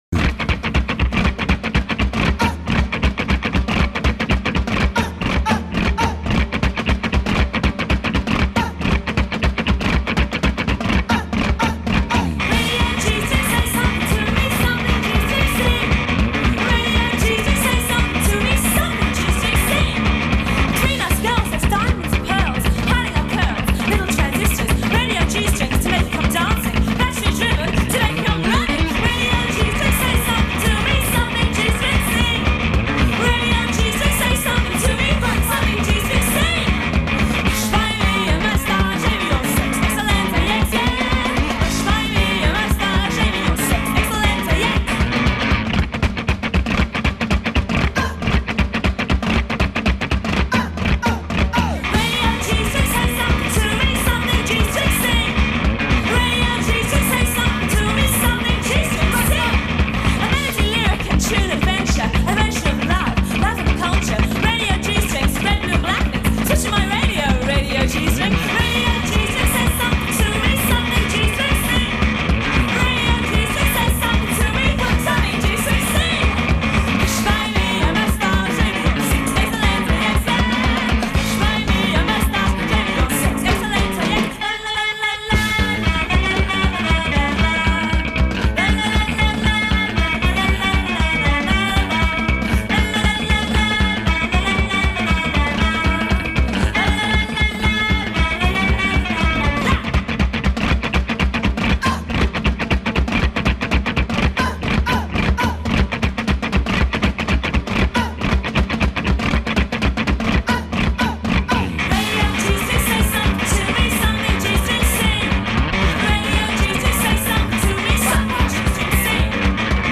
turbo-charged Barundi Mashup.